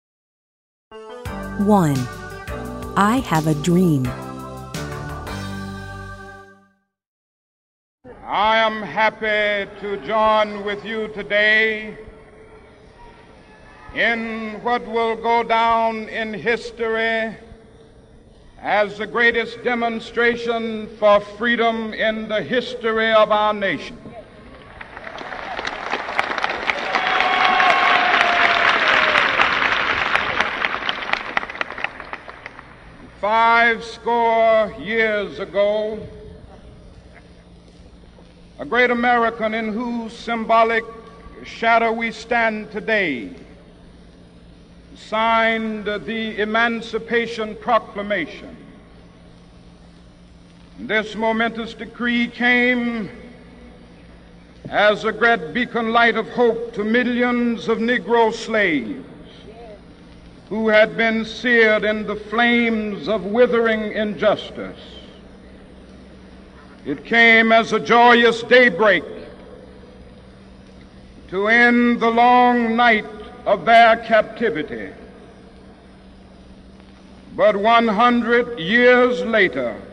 行文間，搭配珍貴的【歷史照片】或精美插圖，並蒐羅【原音重現】的史料音檔，演說慷慨激昂、鏗鏘有力，帶您回到歷史性的那一刻，重溫這些偉大的聲音！
掃描書封QR Code下載「寂天雲」App，即能下載全書音檔，無論何時何地都能輕鬆聽取專業母語老師的正確道地示範發音，訓練您的聽力。